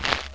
step_a1.mp3